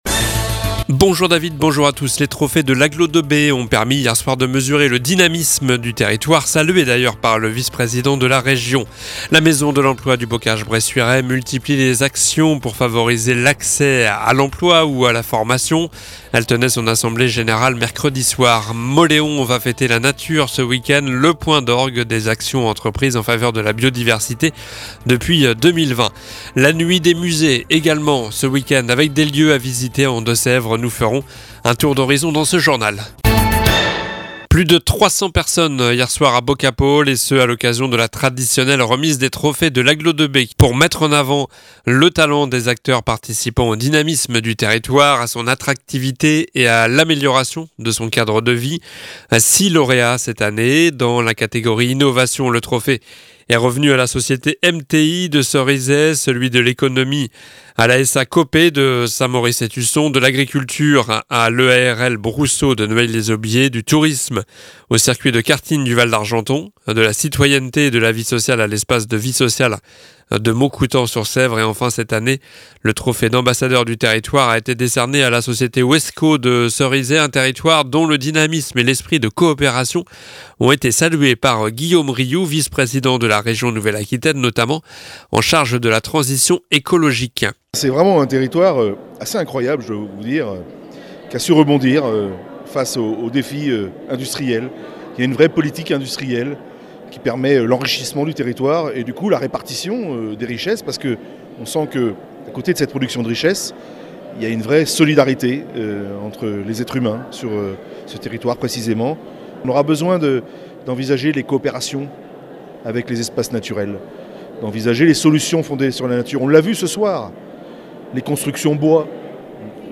Journal du vendredi 12 mai (midi)